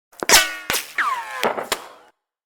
Ricochet Var is a free sfx sound effect available for download in MP3 format.
yt_WFhtO810Cjw_ricochet_var.mp3